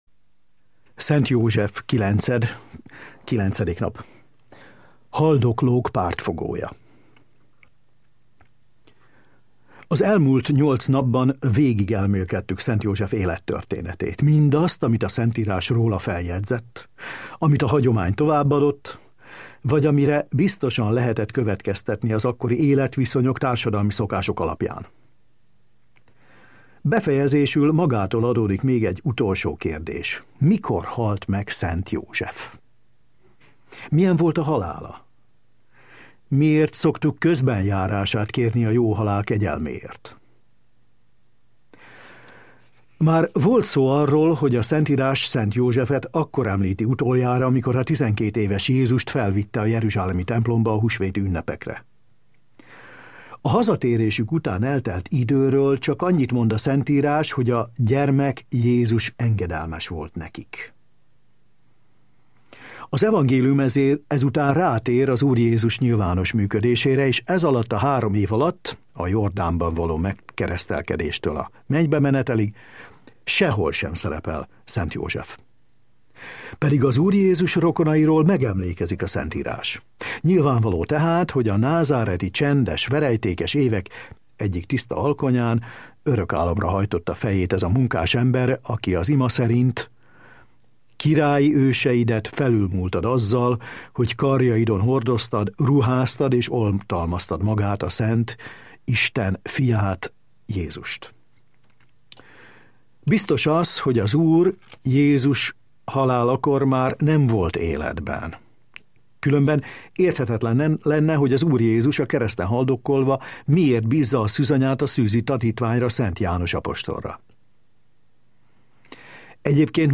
ciszterci szerzetes elmélkedése Szent Józsefről